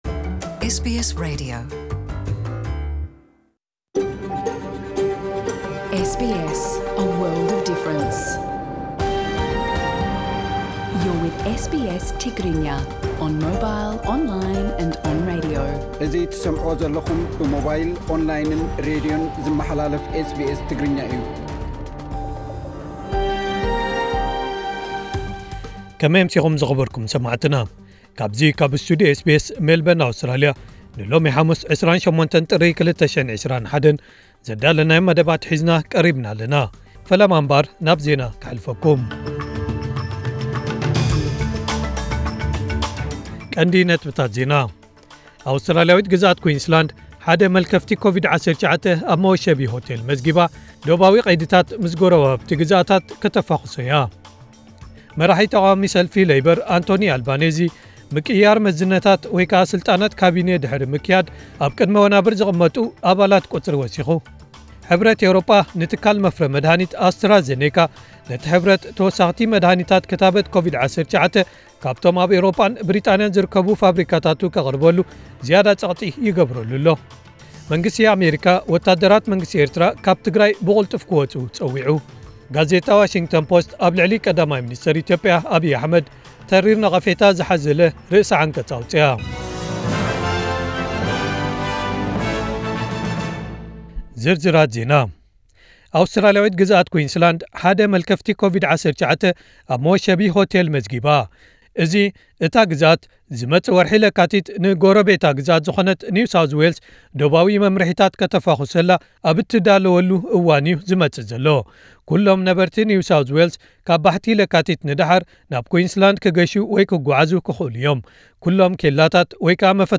ዕለታዊ ዜና ኤስቢኤስ ትግርኛ (28/01/2021)